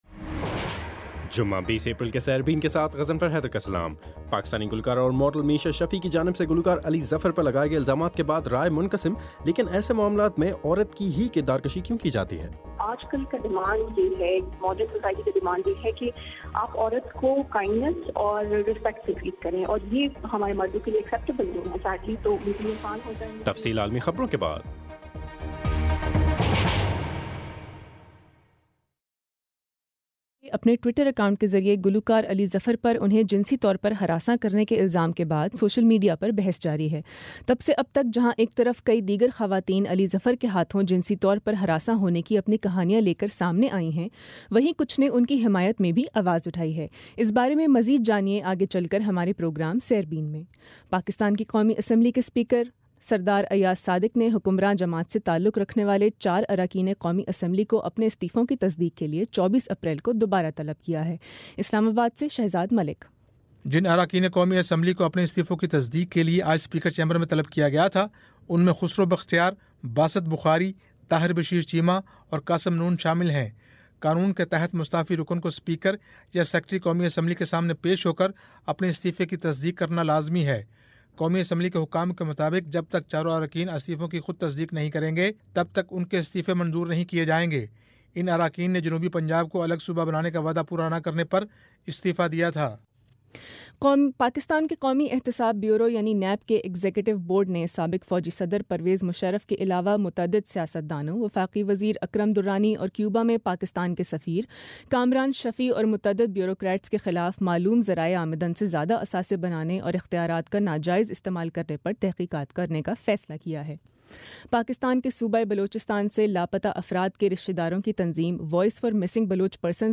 جمعہ 20 اپریل کا سیربین ریڈیو پروگرام